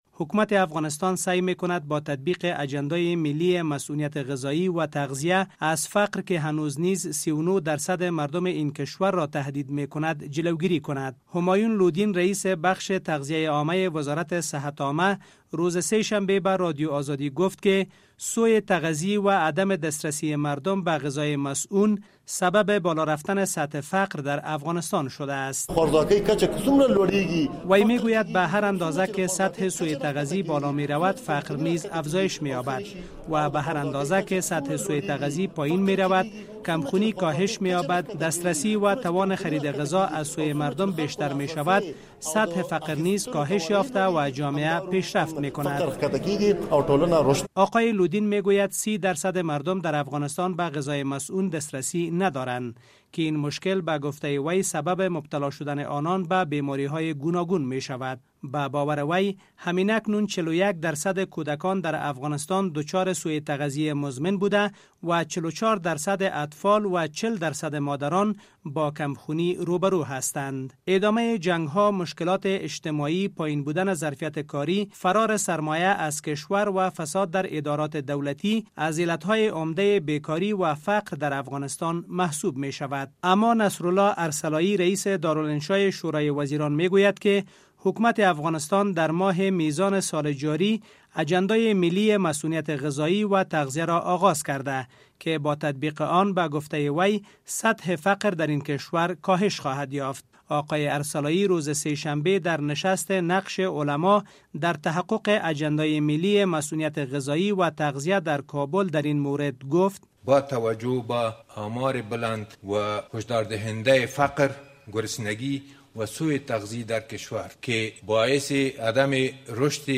گزارش